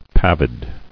[pav·id]